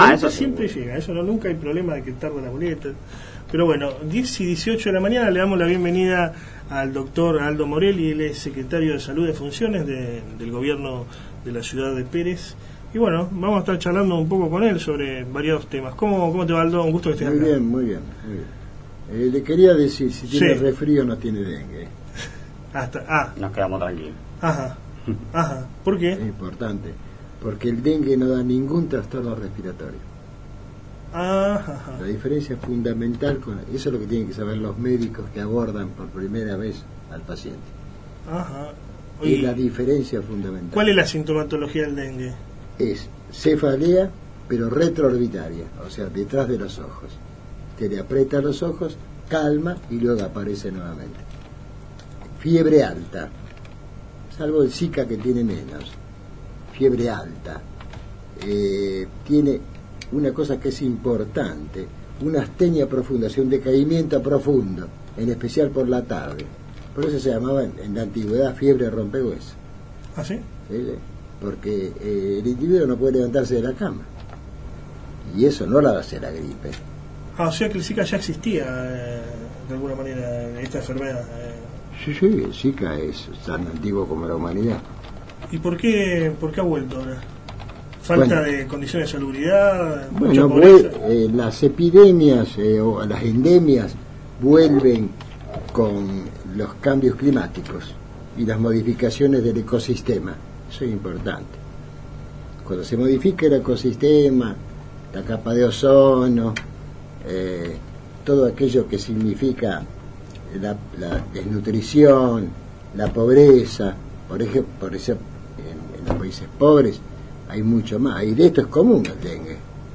Cuna de la Noticia Radio, programa que se emite los días sábados de 10 a 12 hs por FM Eco Radio 92.3 de Rosario, entrevistó al Secretario de Salud de la ciudad de Pérez, Aldo Morelli.